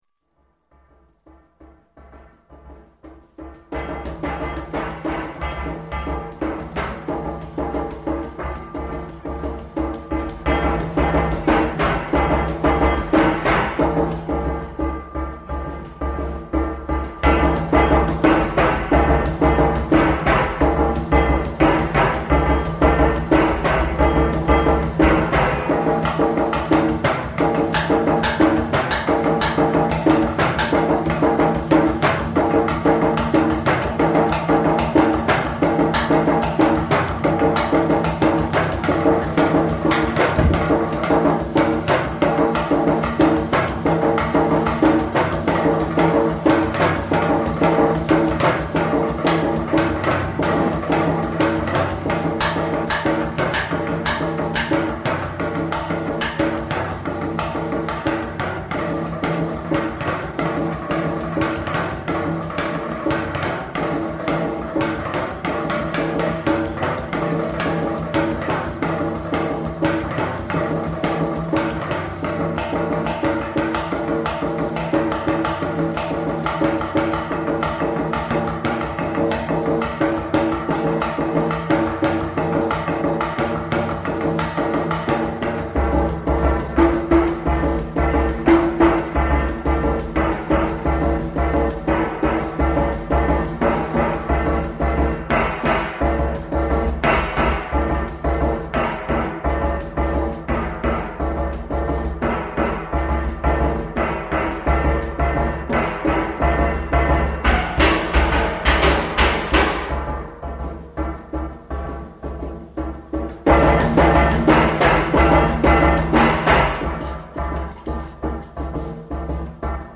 experimental percussion & noise duo   web page
55 gallon drum, metal duct
Army gas can
Percussion jam.